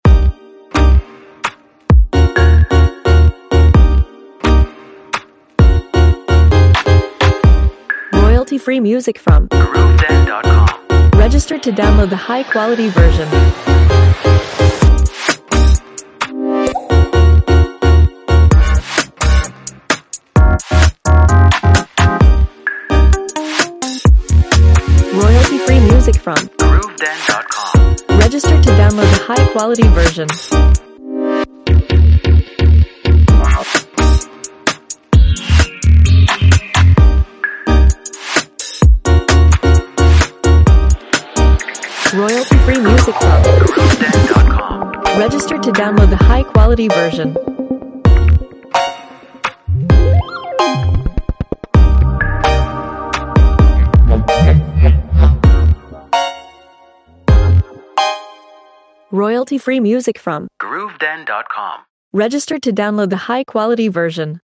Instruments: Synth, drums, percussion, pad, fx.